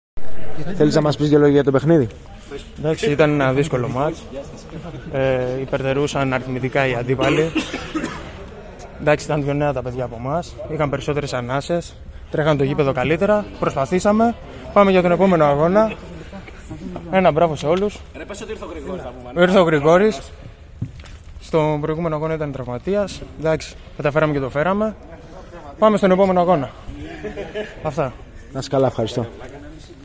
GAME INTERVIEWS:
(Παίκτης Edge Maritime)